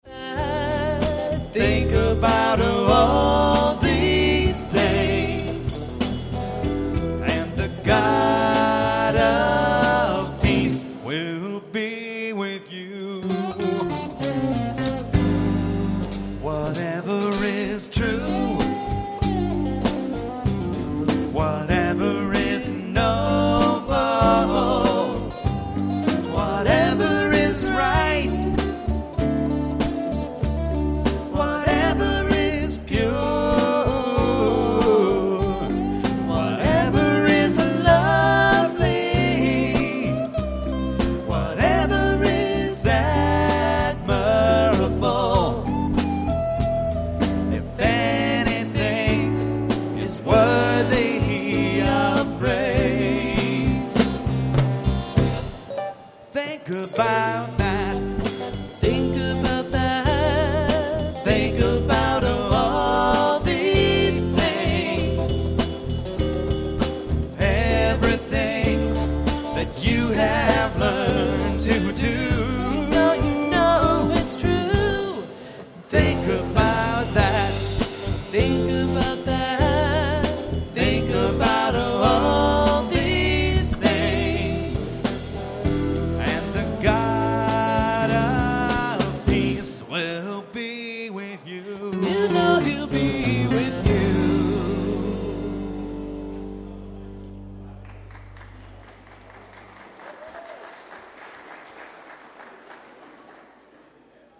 Score Lyrics MIDI MP3 2009 improved demo MP3 live at Faith PowerPoint
whatever_live.mp3